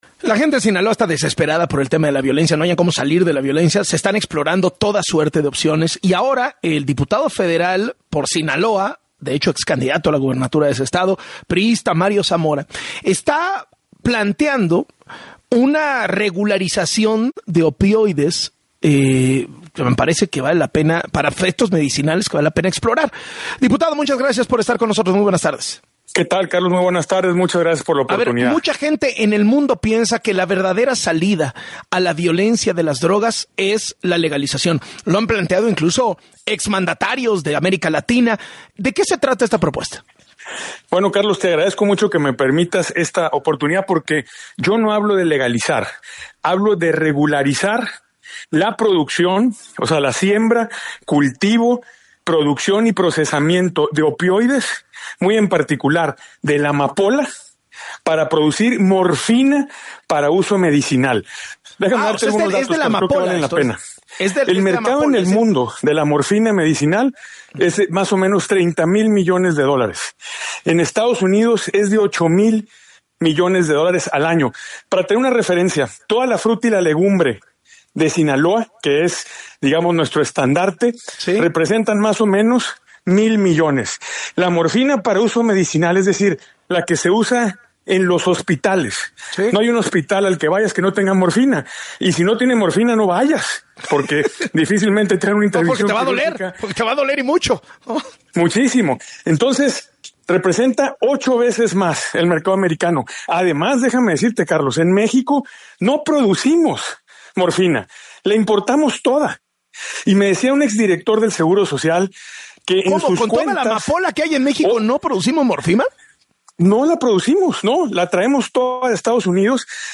En el espacio de “Así las Cosas” con Carlos Loret de Mola, el legislador sinaloense aseguró que el modelo actual en Sinaloa está agotado y que actualmente el discurso público se centra en temas de violencia e inseguridad.